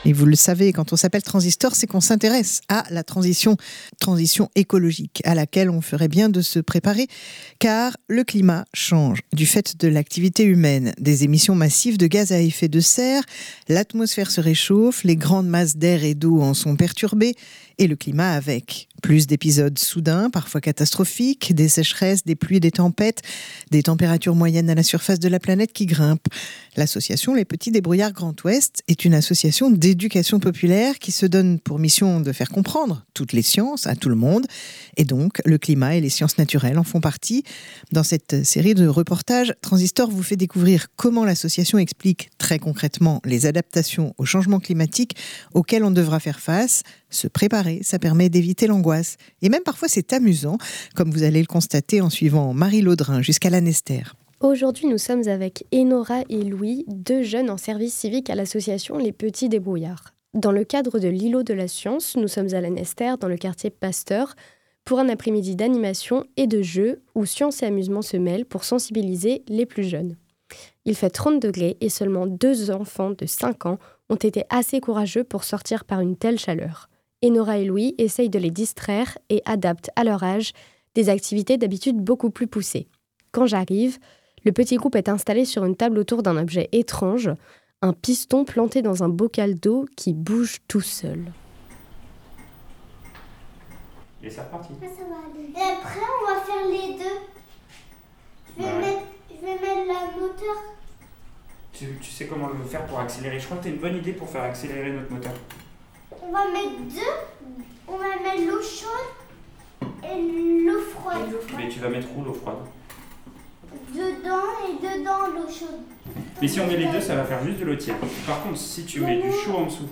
À Lanester, en pleine chaleur, un îlot de sciences proposé par les Petits débrouillards : de l'eau chaude et de l'eau froide avec un moteur Stirling pour faire comprendre la mécanique des fluides et la dilatation de la mer en période de réchauffement, des mesures de température dans la rue et à l'ombre des arbres...
LEMRUB-Petits-debrouillards-adaptation-climat-Lanester-eau-chaleur-reportage.mp3